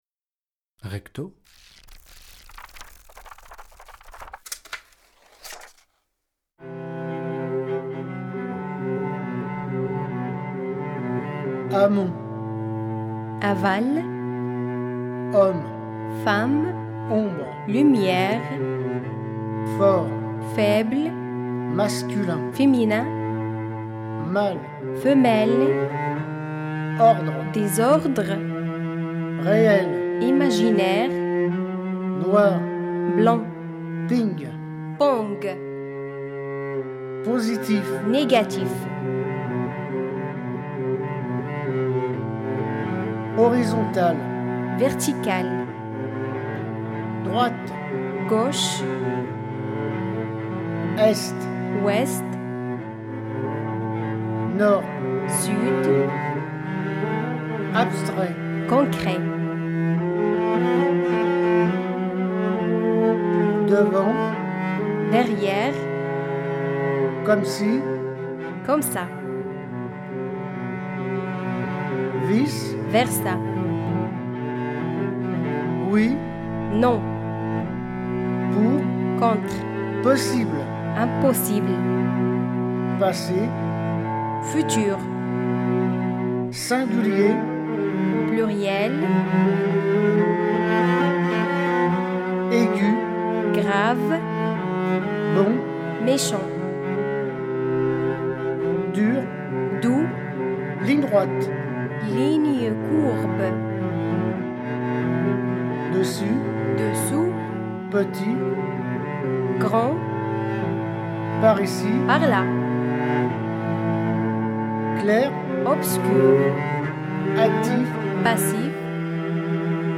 SALUT T'AS LU.mp3 2 violoncelles Papiers à musique Texte